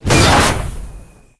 launch_pri_firev2.wav